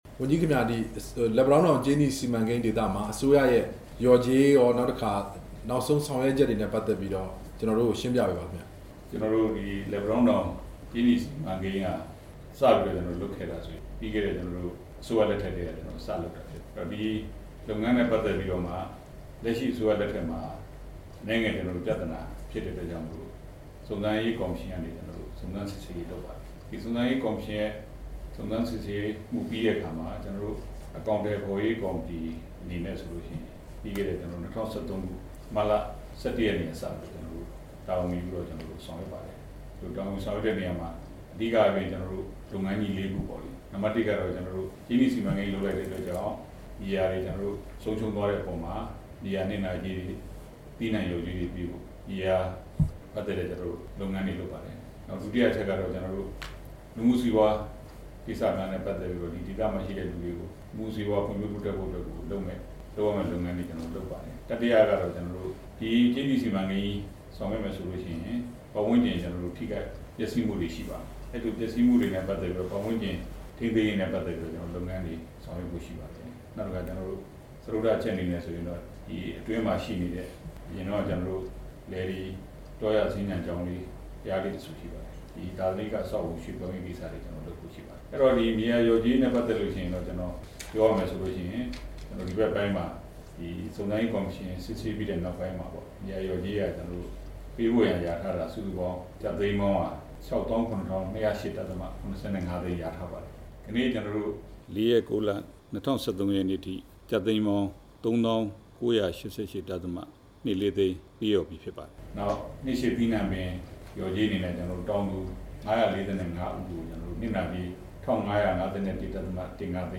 ဒီနေ့ နေပြည်တော် နိုင်ငံတော် သမ္မတရုံးဝန်ြကီးဌာနရုံးခန်းမှာ လက်ပံတောင်းတောင် စုံစမ်းစစ်ဆေးရေး အစီအရင်ခံစာ အကောင်အထည်ဖော်ရေးကော်မတီဥက္ကဌ သမ္မတရုံးဝန်ြကီး ဦးလှထွန်းကို RFA က တွေ့ဆုံမေးမြန်းရာမှာ ဝန်ြကီးက အခုလိုဖြေကြားခဲ့တာပါ။